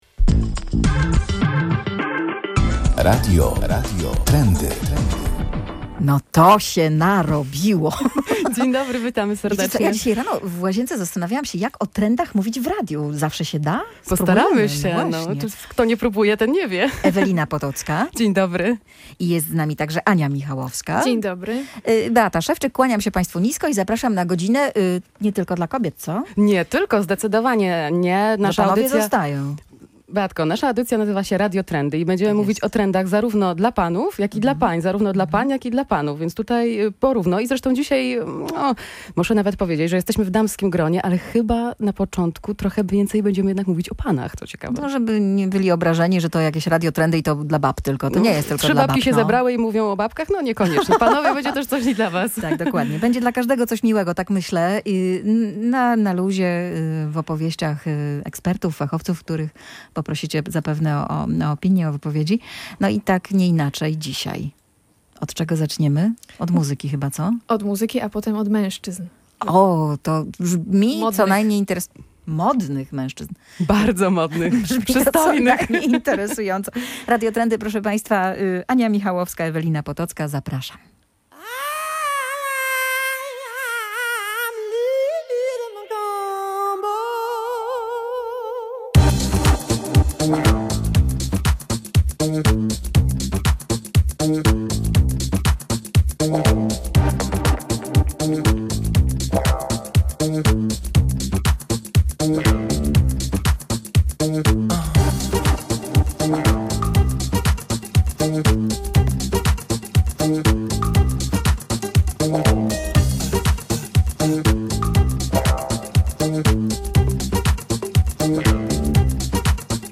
Już rozumiem, jak czują się kobiety, kiedy idą do kosmetyczki – mówił jeden z klientów Barber Shopu w Gdańsku Wrzeszczu, z którym rozmawiała reporterka Radia Gdańsk.